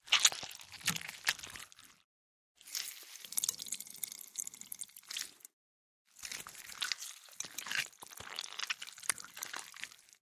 Рукой вынимаем мякоть дыни со звуком